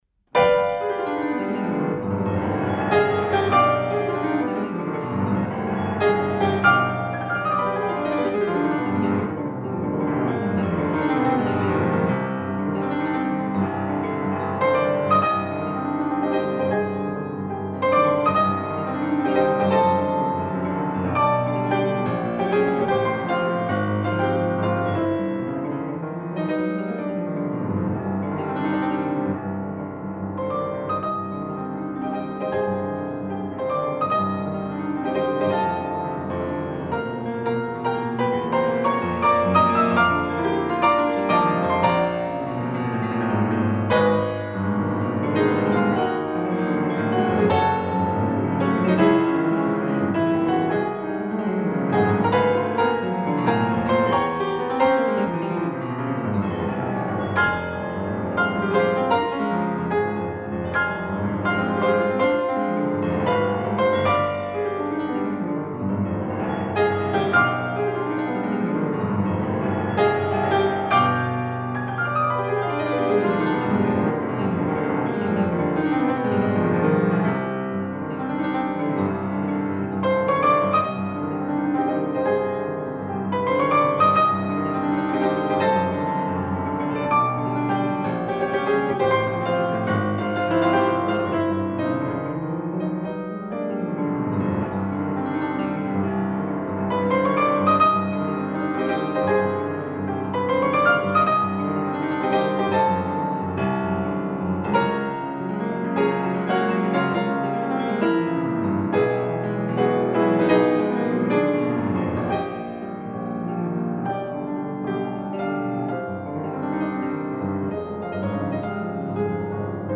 40 Jahre STUMM-Orgelverein Jubiläumskonzert
Récital mit Antonio Pompa-Baldi (Klavier)
Dorfgemeinschaftshaus Sulzbach b. Rhaunen